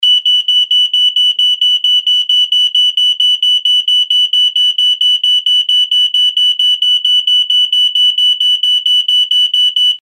rauchwarnmelder-warnton.mp3